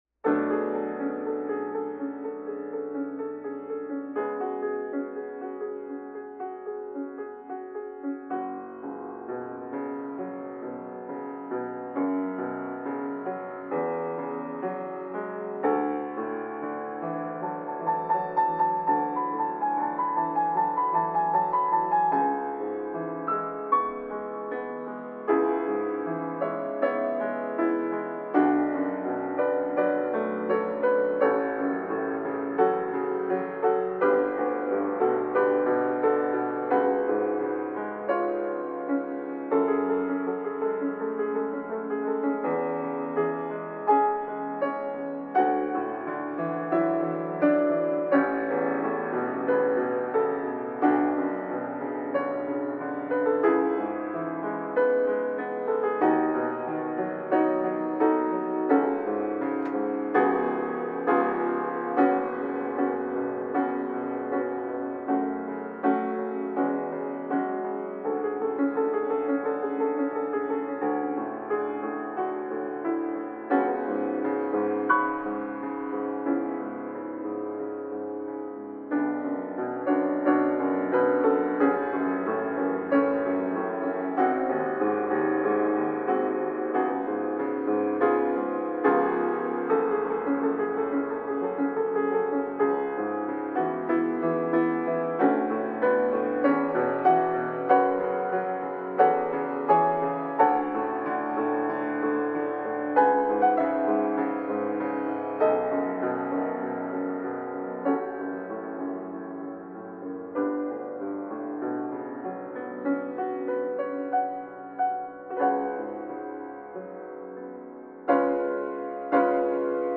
更新履歴 ピアノ即興 mono